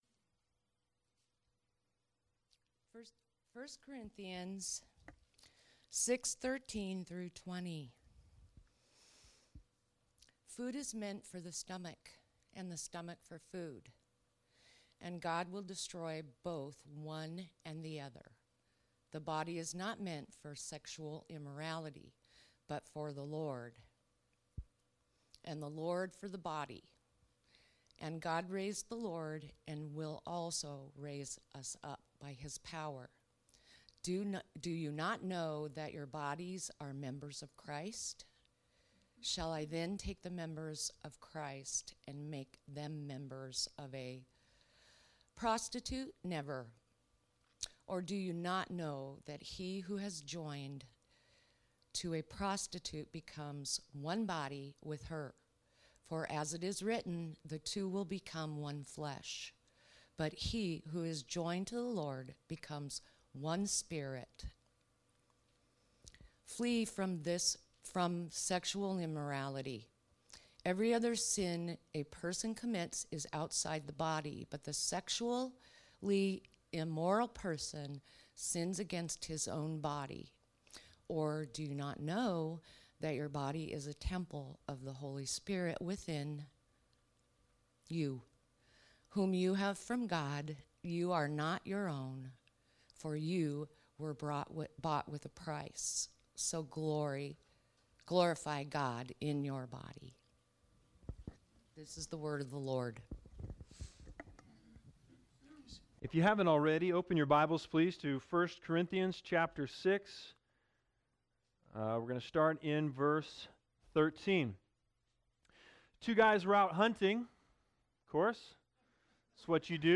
In this sermon, learn the 4 Mindsets that work against the dignity of the body.